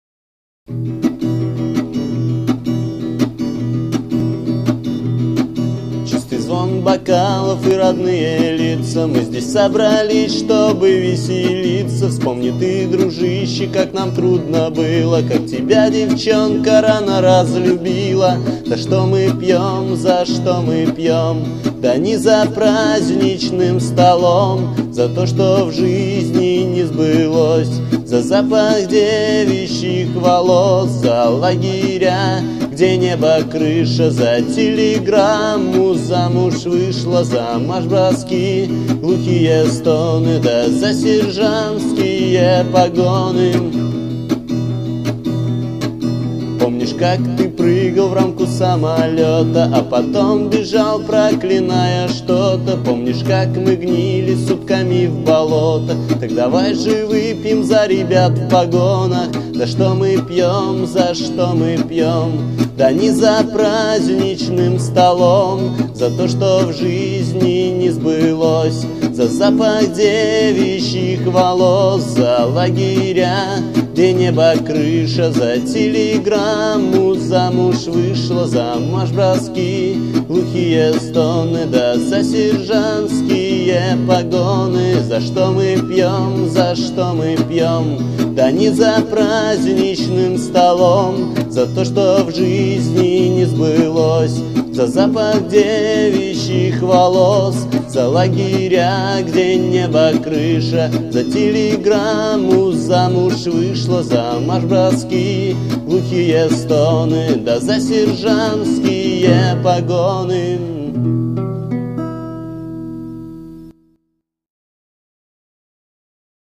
Категория: Красивая музыка » Песни под гитару